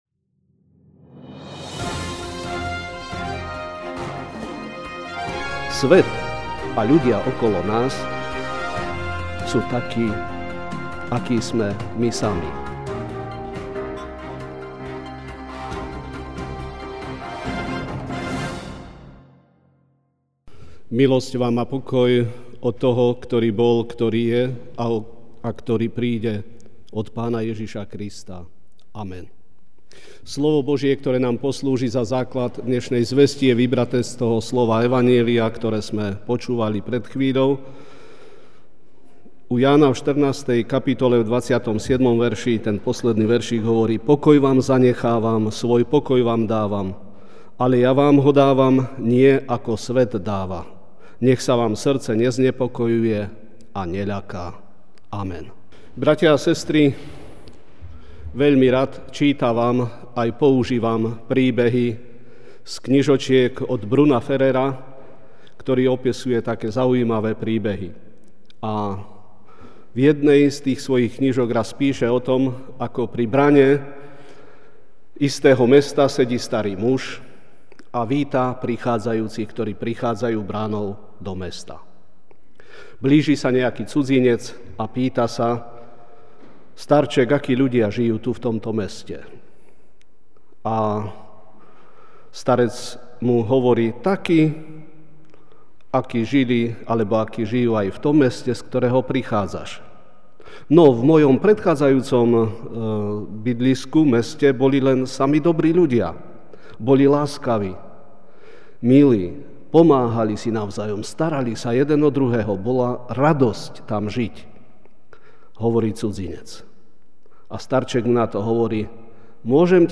Večerná kázeň: Svet navôkol nás (Ján 14, 27) 'Pokoj vám zanechávam, svoj pokoj vám dávam, nie ako svet dáva, vám ja dávam.